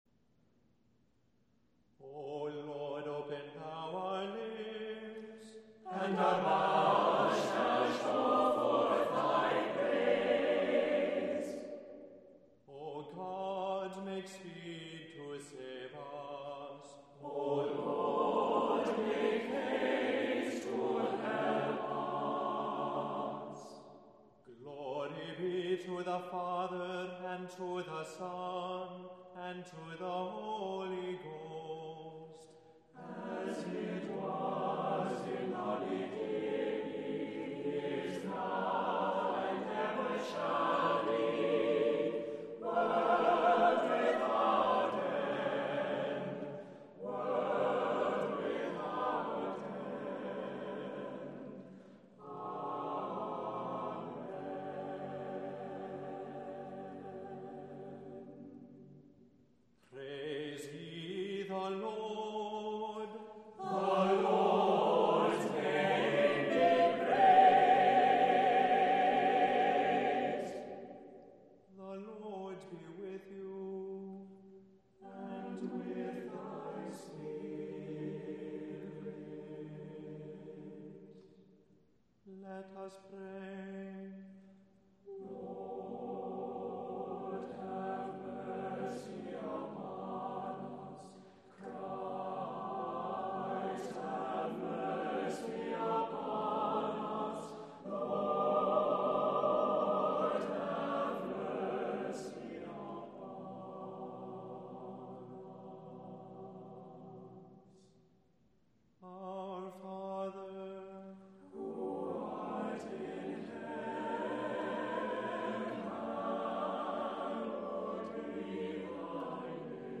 • Music Type: Choral
• Voicing: SATB
• Accompaniment: a cappella
• Liturgical Celebrations: Preces and Responses, Evensong
*Rich sound comes from discreet use of divisi